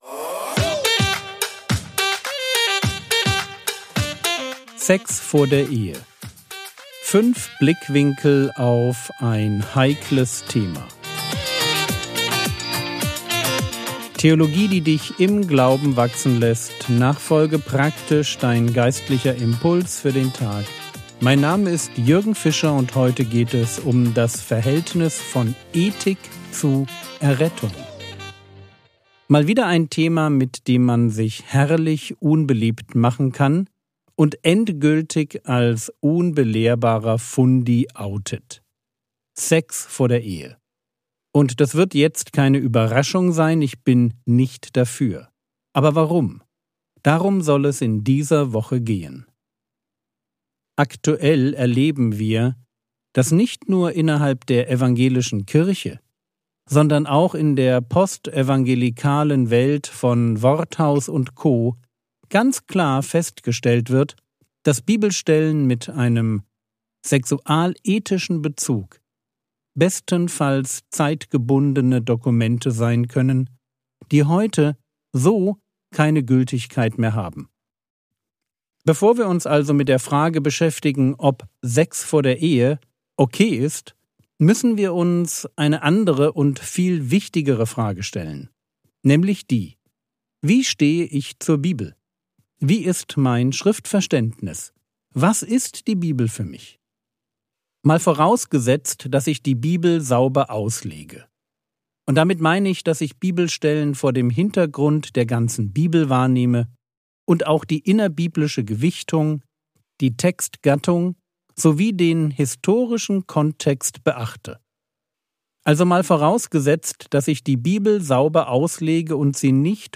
Sex vor der Ehe (1/5) ~ Frogwords Mini-Predigt Podcast